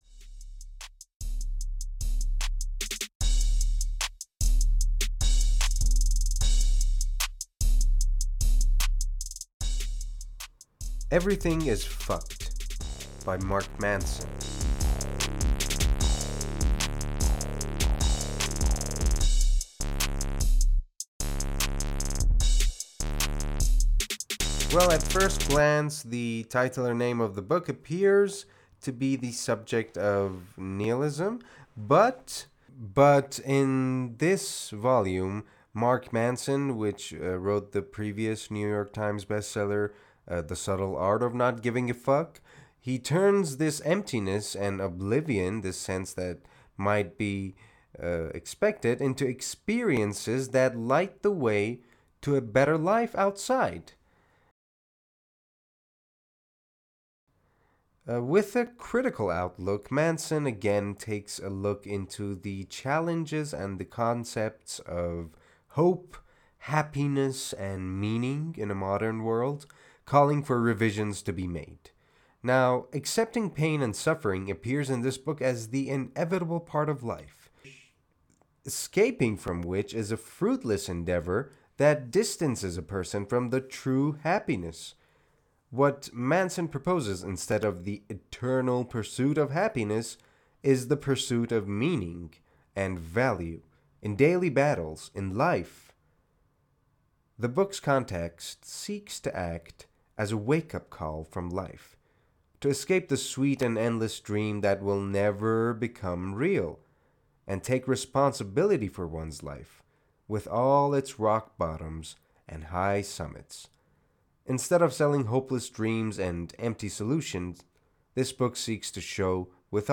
معرفی صوتی کتاب Everything is Fucked